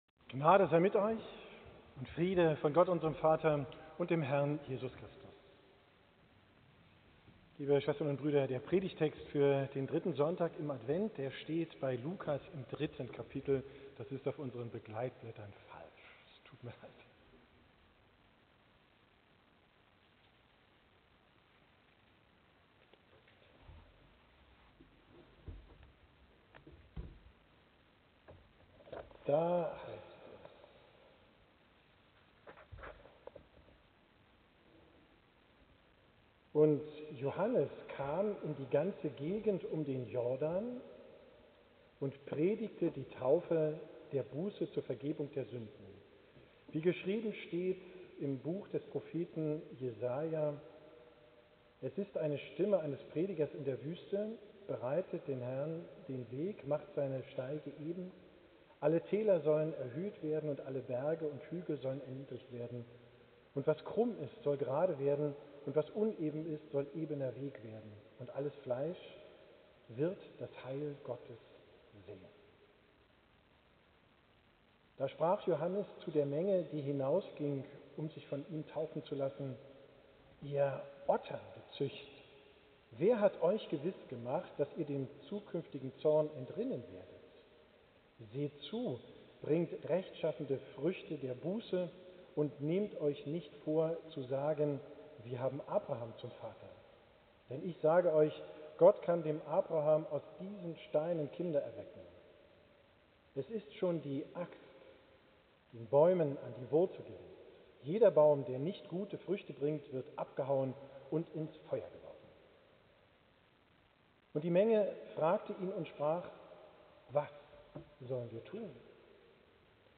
Predigt vom 3.